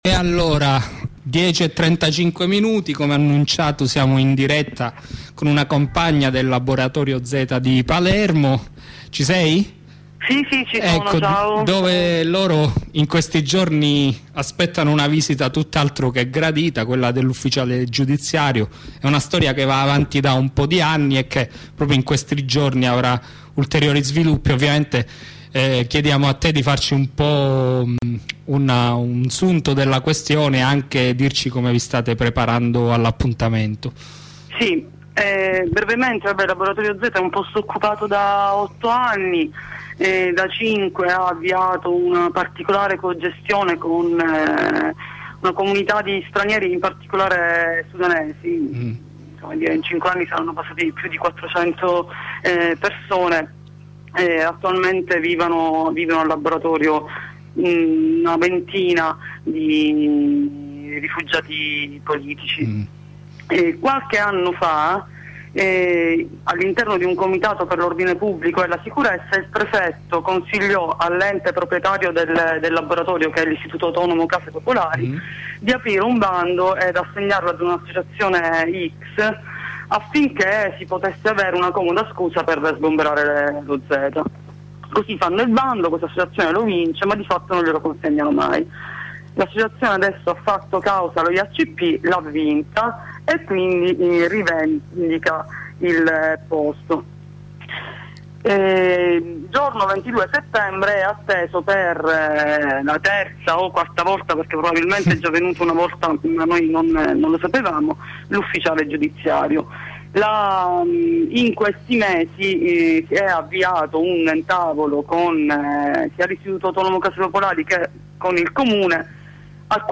Ascolta l'intervista con Radio Onda Rossa